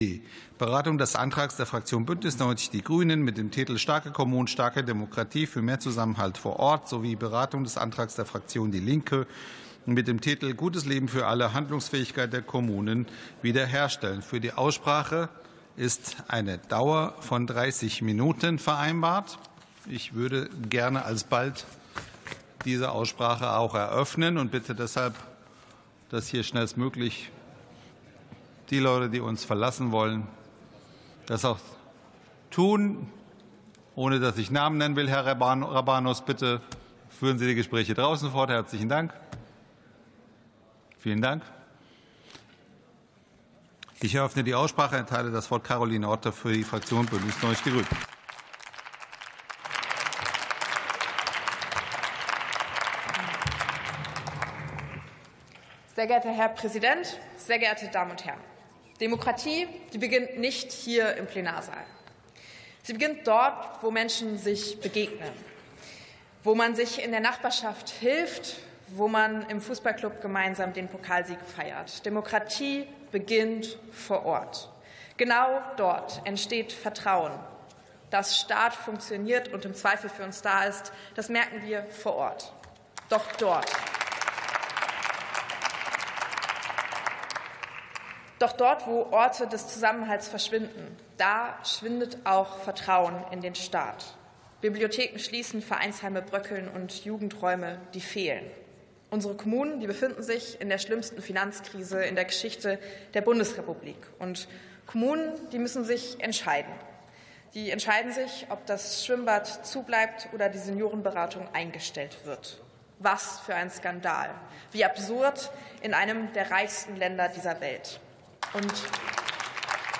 Sitzung vom 06.03.2026. TOP 17: Kommunalpolitik ~ Plenarsitzungen - Audio Podcasts Podcast